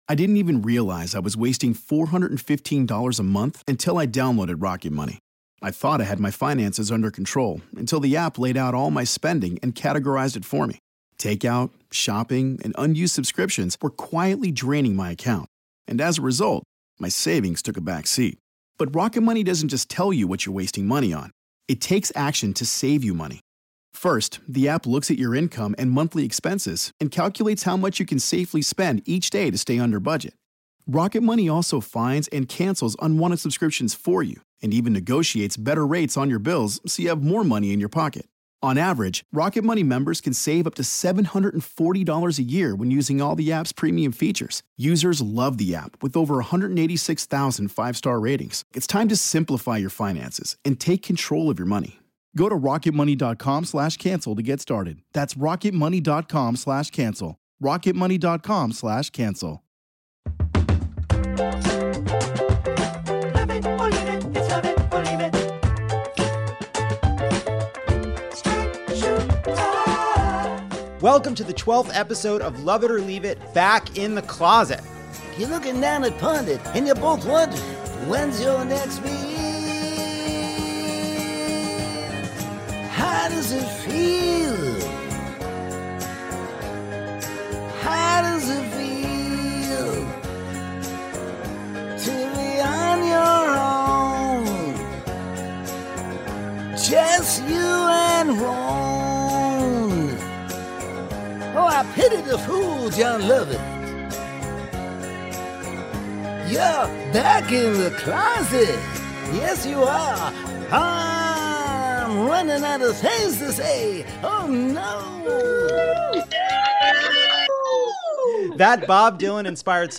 And we're joined live by listeners in Birmingham to break down the week's news and quiz the audience on how characters are described in books versus how those characters are cast on screen.